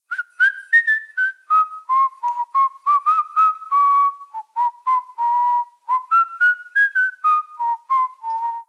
Mujer silbando 1
silbido
Sonidos: Acciones humanas